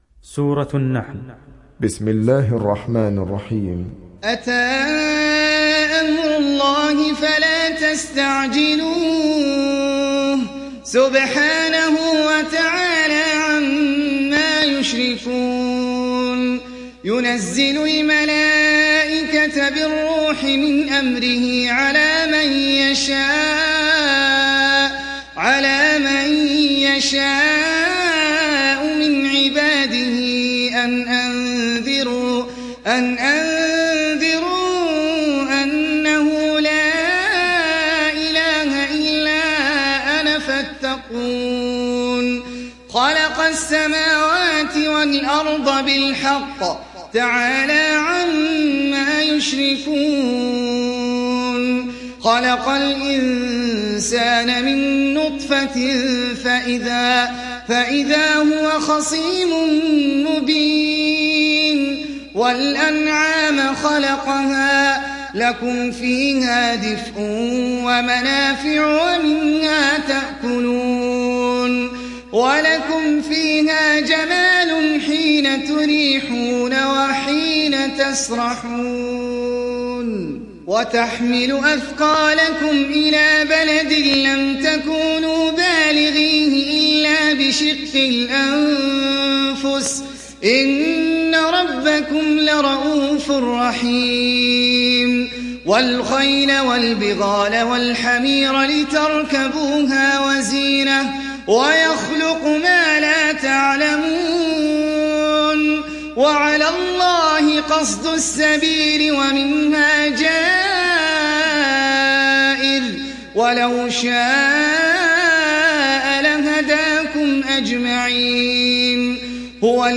تحميل سورة النحل mp3 بصوت أحمد العجمي برواية حفص عن عاصم, تحميل استماع القرآن الكريم على الجوال mp3 كاملا بروابط مباشرة وسريعة